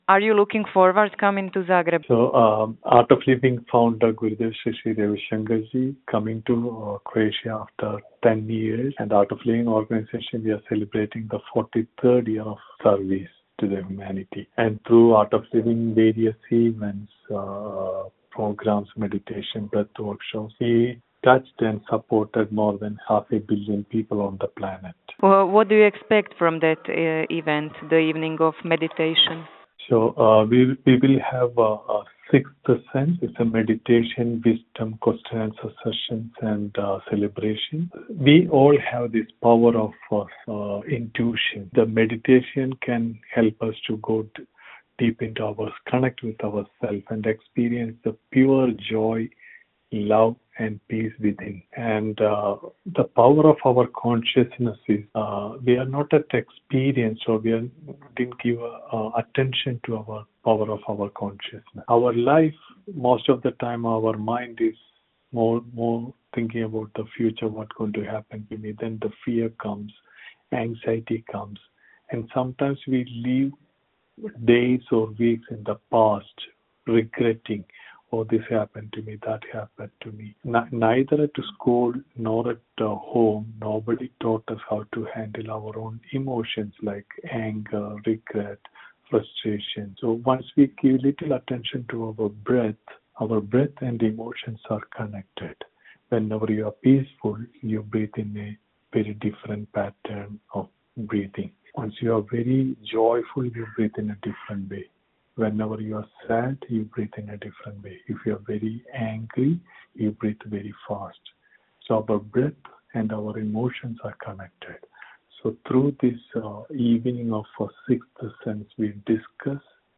Cijeli razgovor